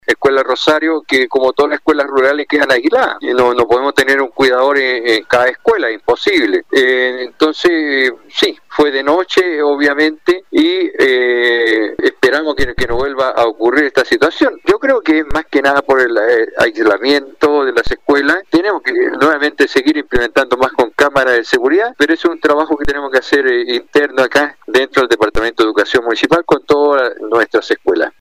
En tanto en la comuna de Calbuco, el alcalde de esa ciudad Rubén Cárdenas, indicó que se trata de la Escuela Rural el Rosario, donde en horas de la noche los antisociales ingresaron y sustrajeron diversas especies, por lo que ahora tendrán que adoptar sus propias medidas de seguridad.